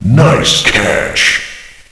nicecatch.ogg